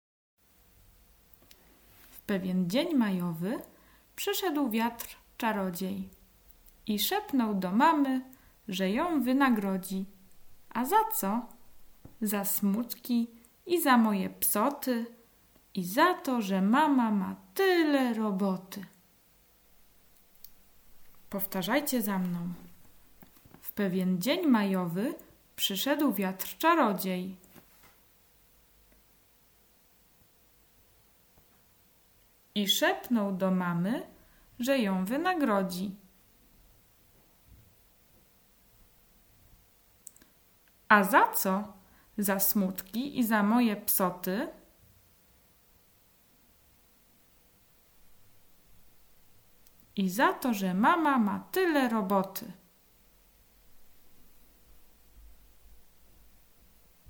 Poniżej znajdują się nagrania trzech krótkich wierszyków wraz z pauzami do nauki i powtarzania przez dziecko.